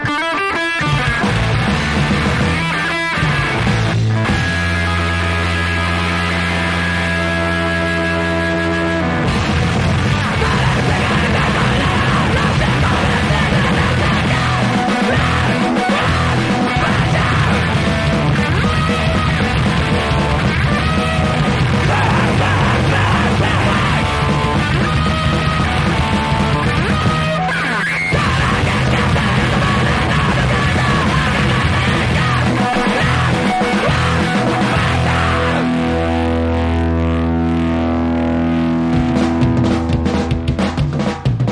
625 THRASHCORE
BASS
DRUMS
GUITAR
VOCALS